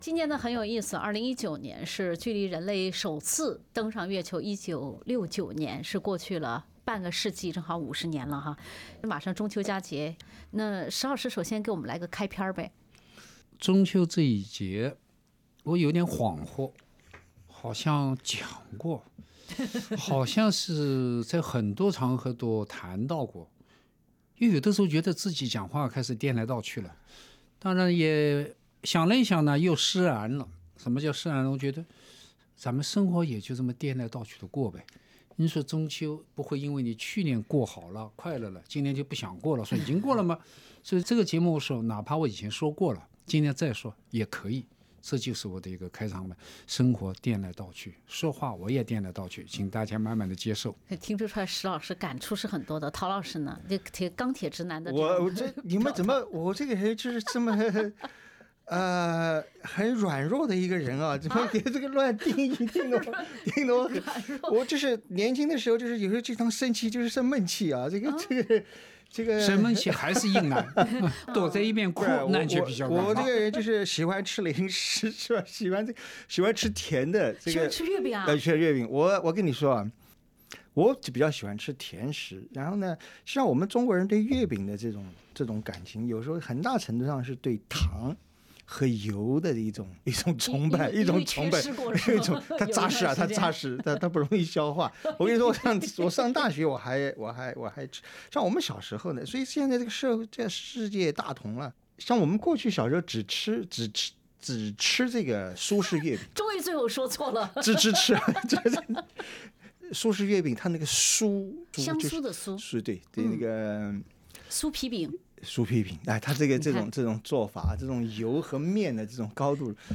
在人类登月50周年的中秋之际，文化苦丁茶三人组在没有月饼香茗的陪伴下，并不寡淡地继续无酒问青天。